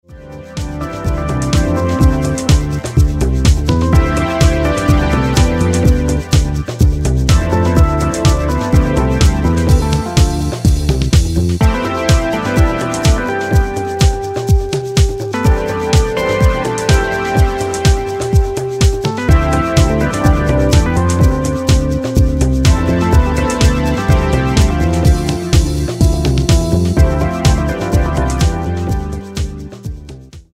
is a positive, melodic disco electro track
has a unique sound not found in typical license music.